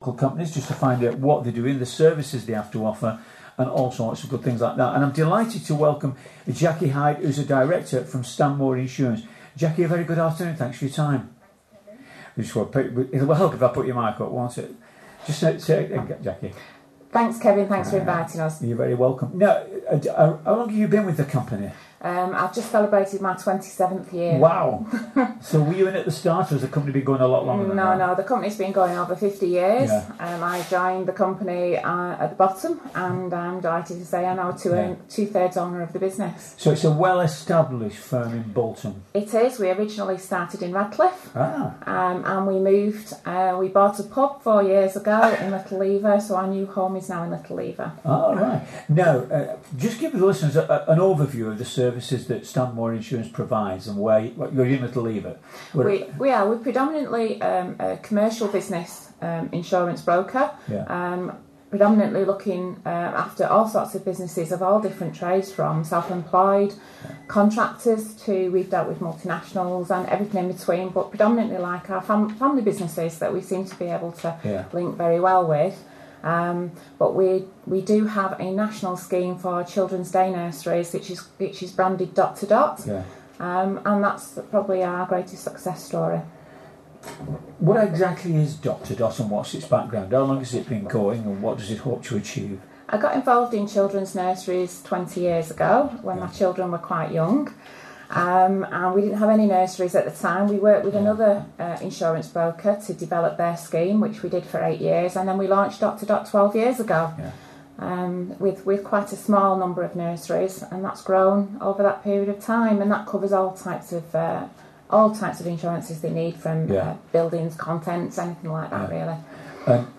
Bolton FM Interview – EVA Awards